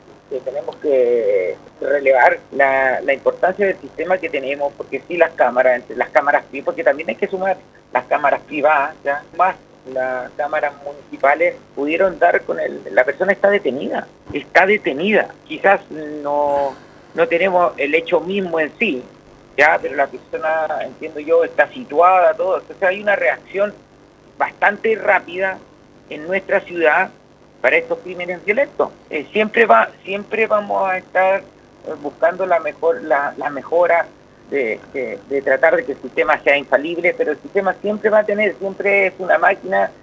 En una conversación con Radio Paulina, Soria aclaró que, si bien hubo un desperfecto con el sistema de grabación en uno de los homicidios, el sistema de cámaras continúa siendo uno de los más amplios y efectivos en Chile.